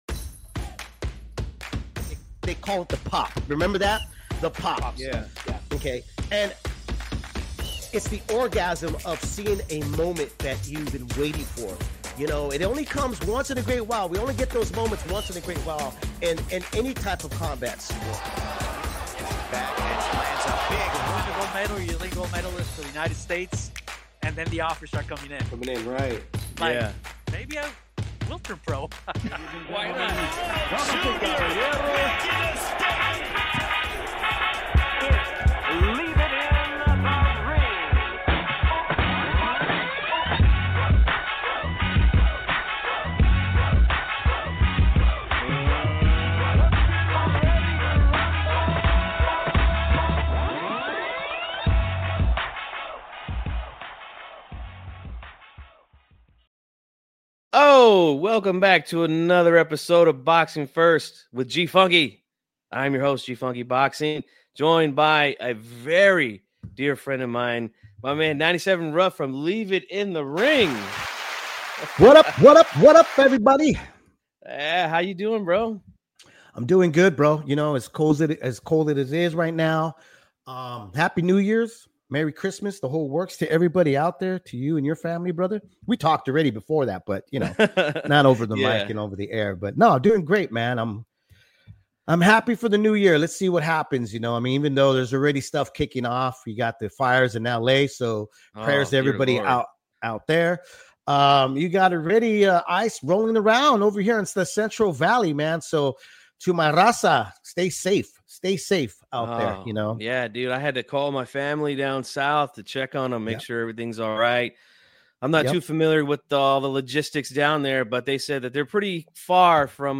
We provide some of the best and most exclusive interviews to the Boxing World, they include real hardcore boxing fans views to the listeners with a behind the scene inside take.